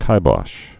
(kībŏsh, kī-bŏsh)